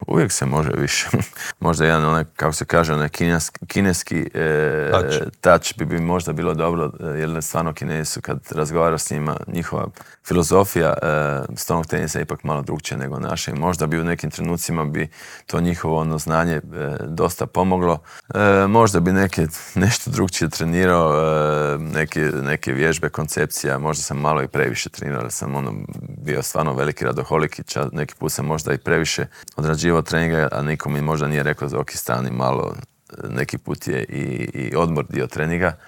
U dvorani Krešimira Ćosića na Višnjiku očekuje nas ekipno prvenstvo Europe, a u Intervjuu tjedna Media servisa ugostili smo predsjednika Hrvatskog stolnoteniskog saveza Zorana Primorca